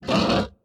animalia_pig_death.ogg